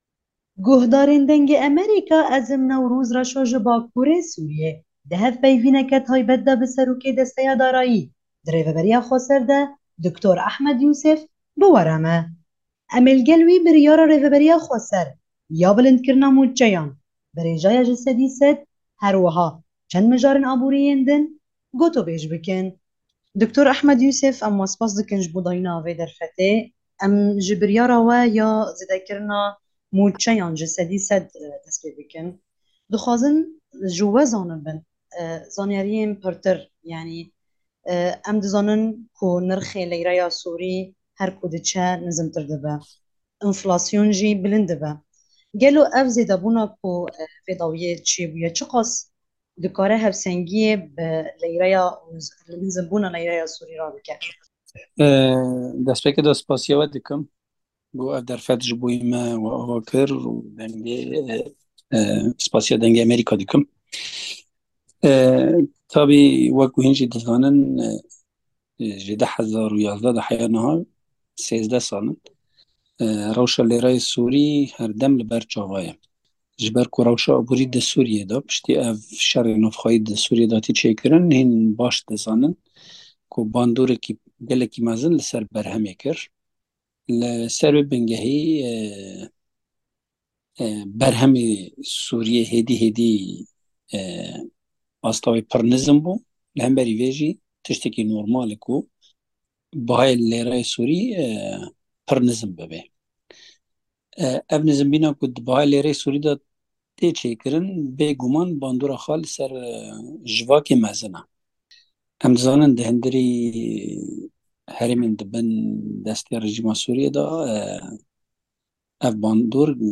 Rêvebirîya Xweser ya Bakur û Rojhilata Sûrîyê roja Yekşemê biryar da ku mûçeyên hemî karmend û endamên xwe bi rêjeya % 100 bilind bike. Serokê Desteya Darayî Dr. Ahmed Yûsif, di hevpeyvîneke taybet de ji Dengê Amerîka re got ku pirtir ji 250 hezar karmend sûdê ji zêdebûna mûçeyan dibînin,...